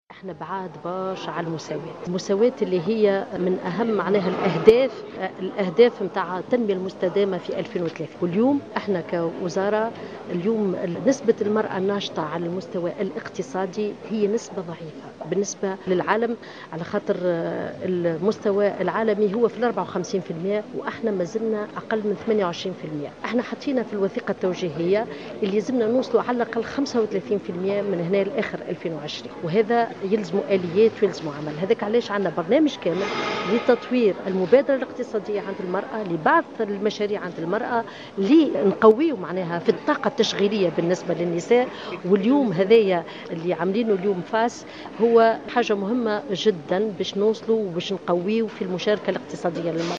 وأضافت مرعي في تصريح لمراسلة الجوهرة أف أم